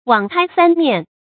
注音：ㄨㄤˇ ㄎㄞ ㄙㄢ ㄇㄧㄢˋ
網開三面的讀法